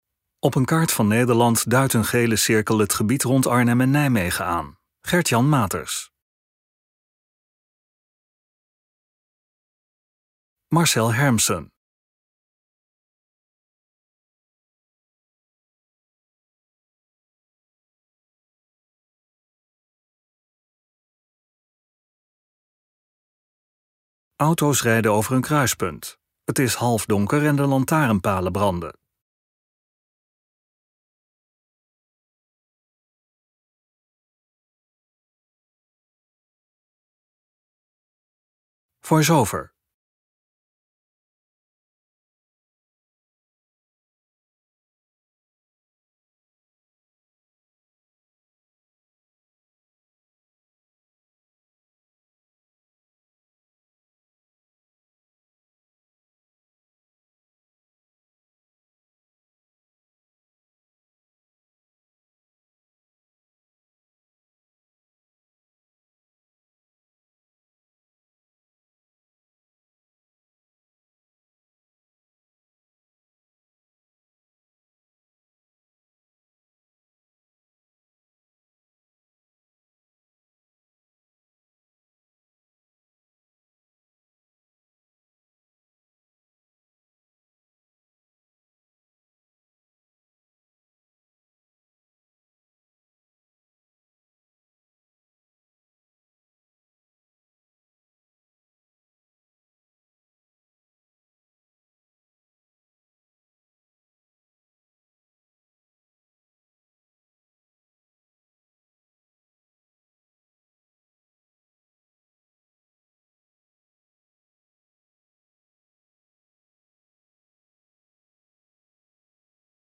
In deze video vertelt voormalig gedeputeerde Conny Bieze van de provincie Gelderland over het belang van de nieuwe verbinding tussen de bestaande A15 en A12.
In de film is ook aandacht, via een bewoner en ondernemer, voor de zorgen rondom de toekomstige werkzaamheden en de geplande tolheffing.